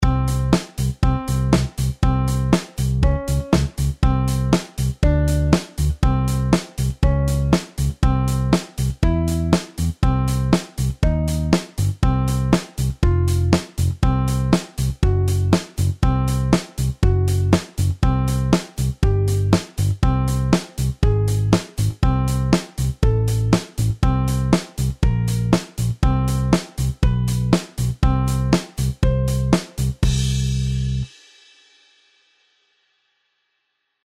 Нажимая клавиши последовательно, мы создаём мелодию, поэтому такой интервал называют "Мелодическим".
melodic_intervals.mp3